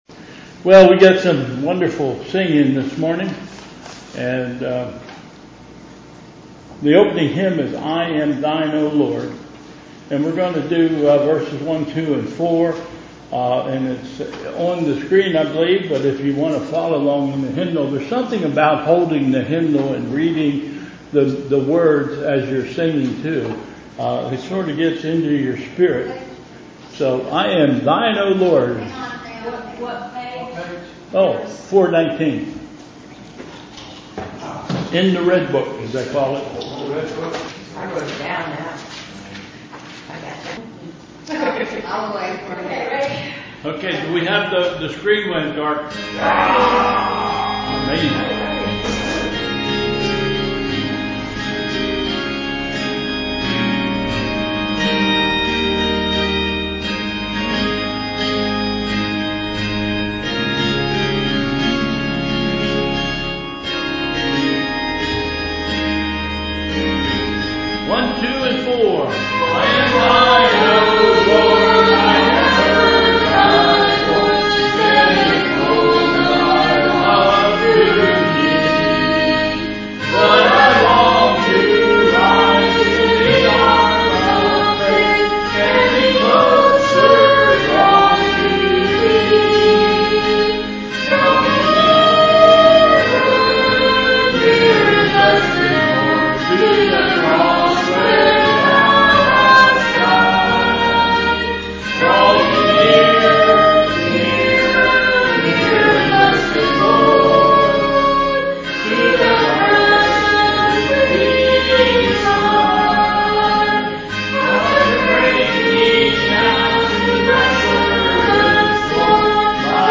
Bethel Church Service
Hymn: "I Am Thine, O Lord"